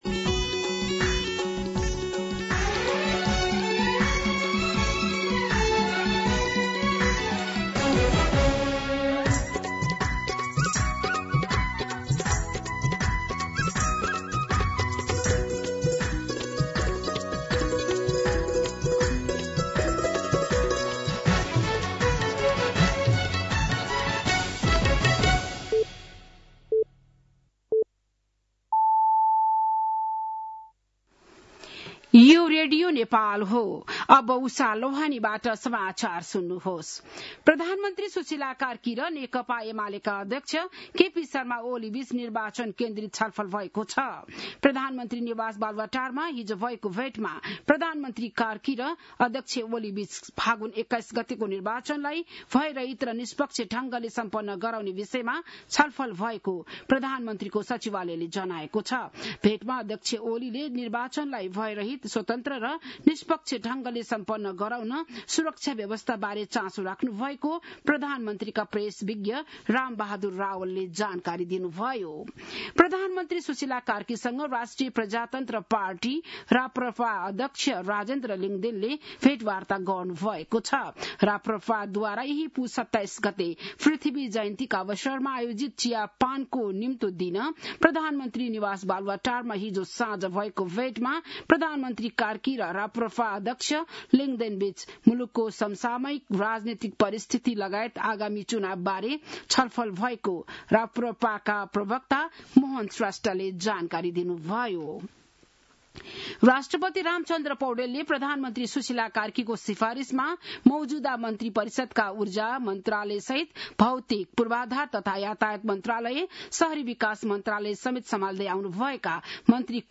बिहान ११ बजेको नेपाली समाचार : २५ पुष , २०८२